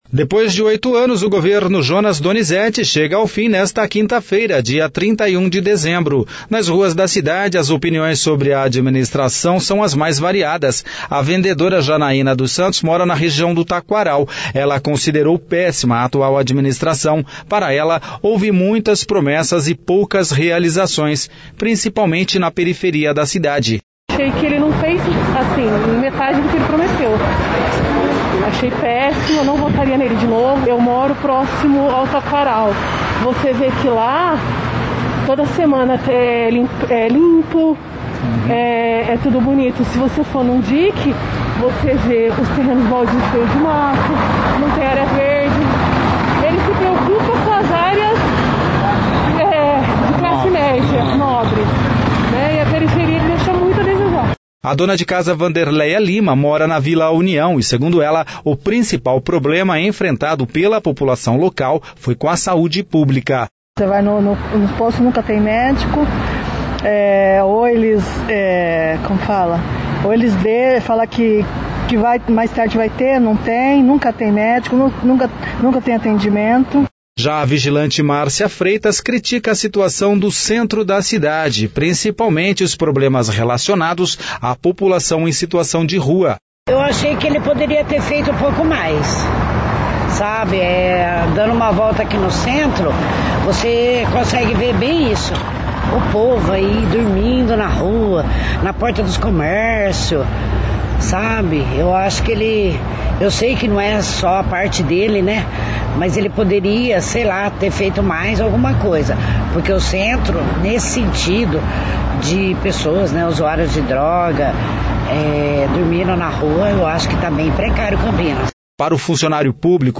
Depois de oito anos o governo Jonas Donizette chega ao fim nesta, quinta-feira, dia 31. Nas ruas da cidades as opiniões sobre a administração são as mais variadas.